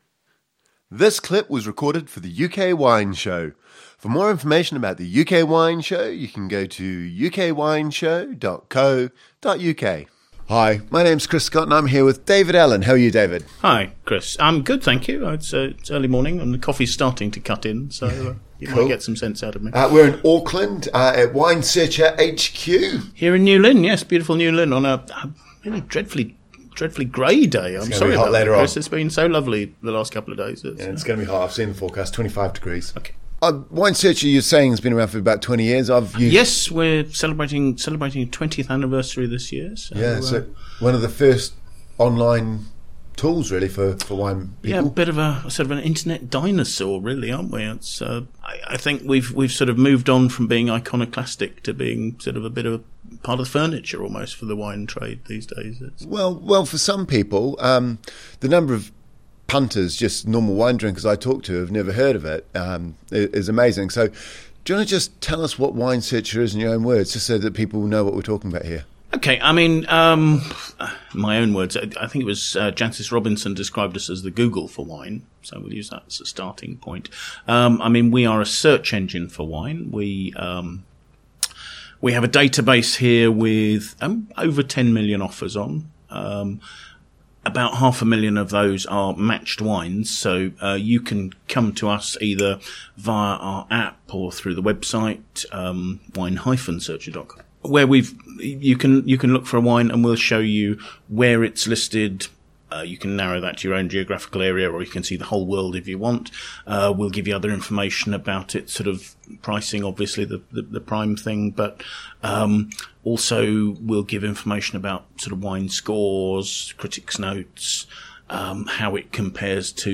Listen to the full UK Wine Show